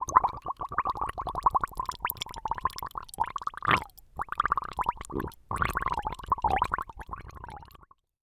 human
Human Male Gargles Bubbles